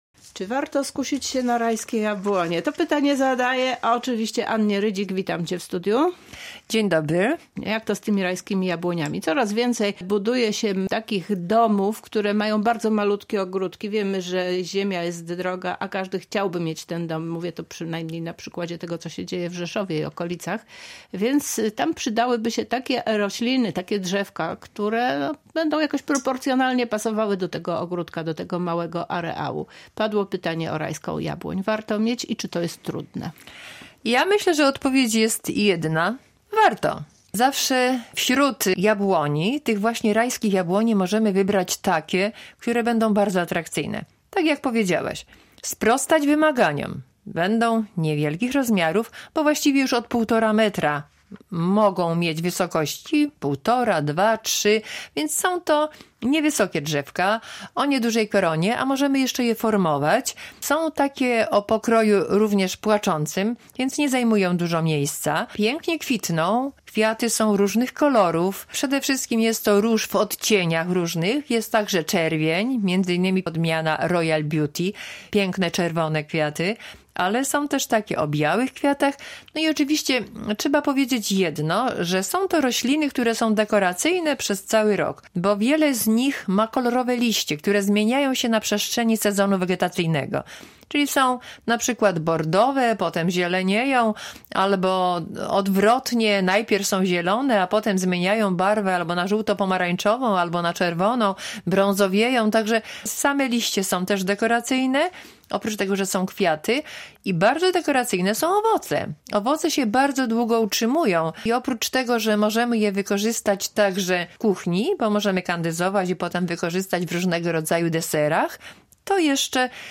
Zapraszamy do wysłuchania rozmowy o tych drzewkach.